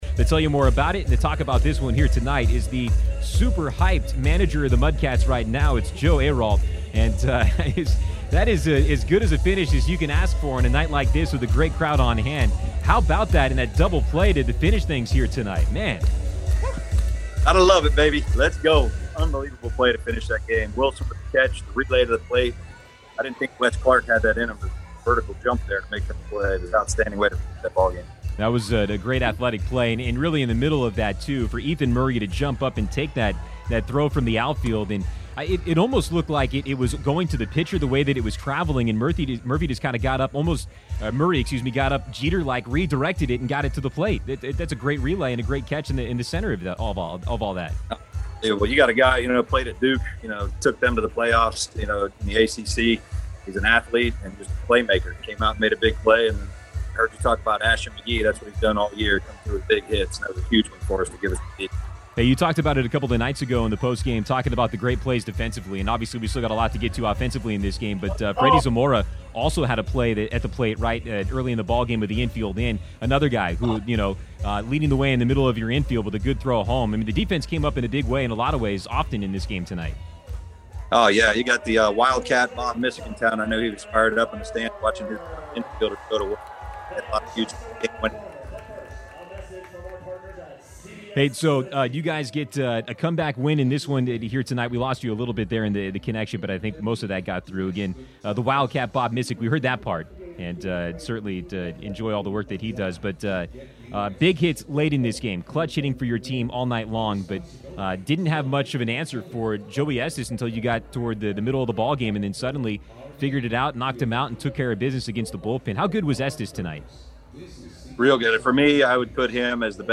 AUDIO: Post-Game Interview